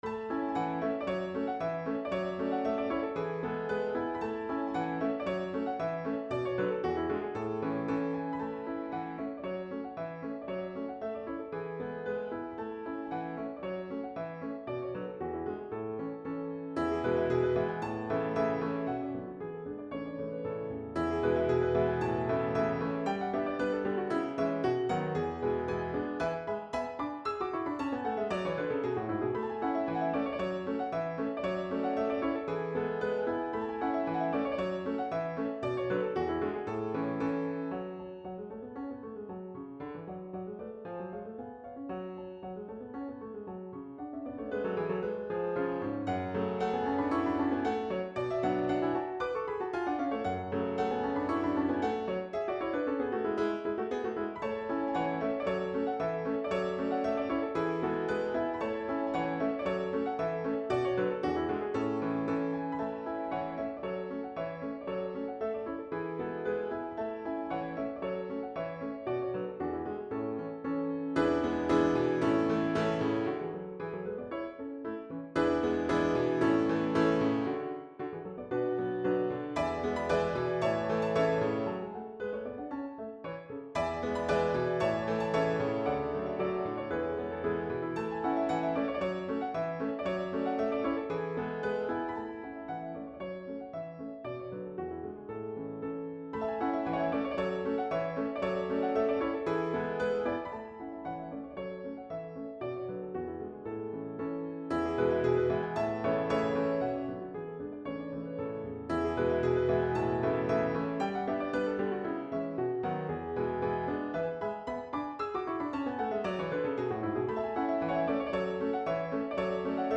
As the title suggests this is a polka but not what one would ordinarily associate with the typical “In Heaven There Is No Beer” kind of polka that generally comes to mind.
The polka is composed in the key of A major. It is in Rondo form: AA,BB,AA,CC,AA,DD,AA,BB,A,Coda.